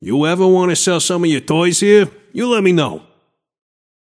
Shopkeeper voice line - You ever wanna sell some of your toys here, you let me know.